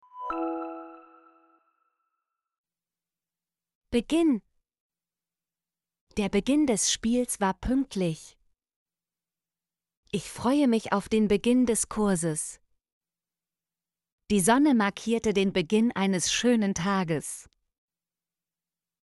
beginn - Example Sentences & Pronunciation, German Frequency List